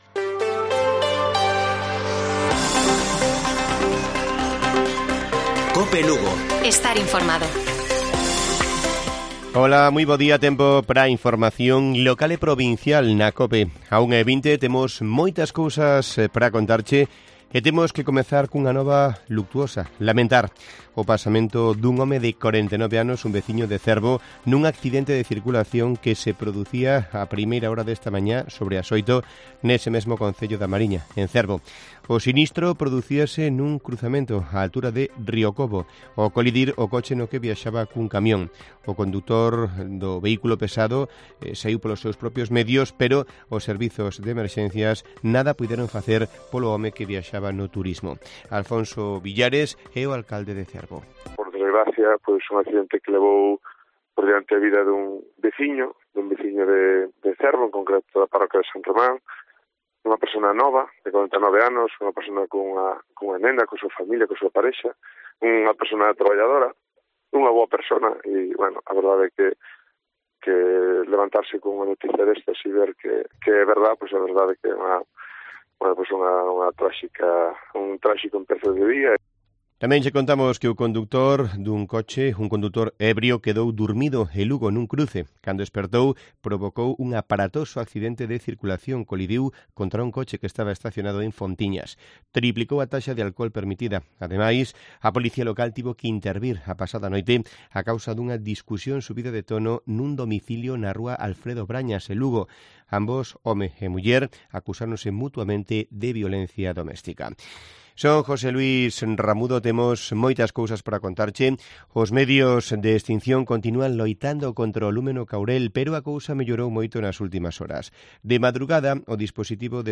Informativo Mediodía de Cope Lugo. 22 DE JULIO. 13:50 horas